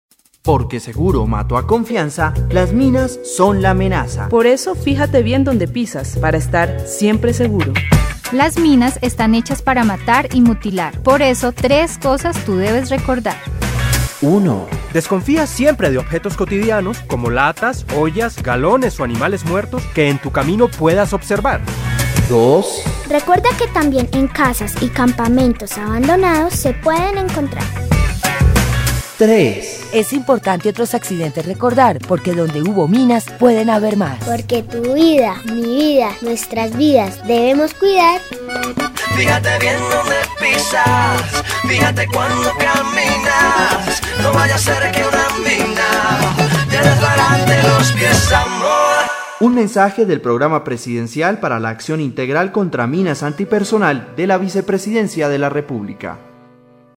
La campaña incluye siete comerciales de televisión sobre los riesgos y las orientaciones para asumir comportamientos seguros; cinco cuñas radiales con los mensajes básicos de prevención y las voces de importantes artistas como Maia y el grupo San Alejo, entre otros.
Cuñas radiales